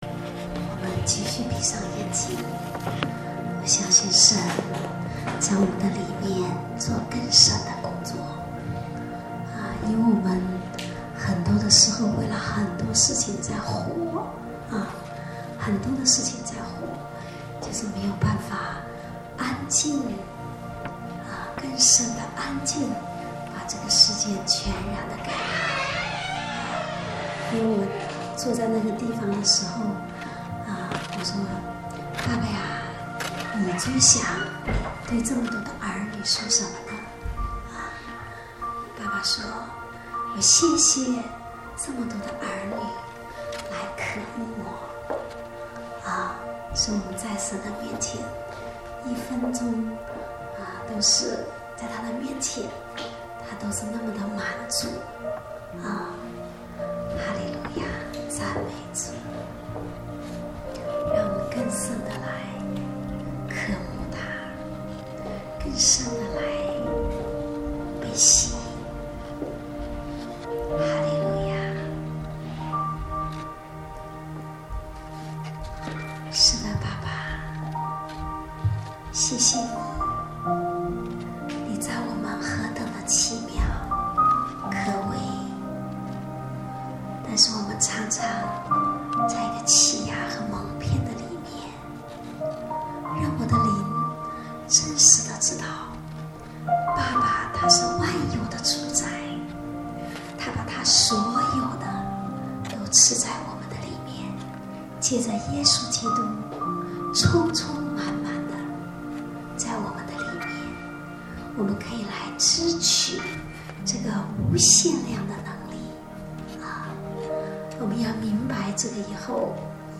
主日恩膏聚会录音（2015-08-23）